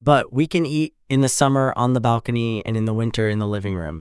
Text-to-Speech
Synthetic